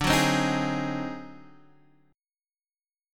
D 7th Flat 9th Flat 5th
D7-9-5 chord {x 5 4 5 4 4} chord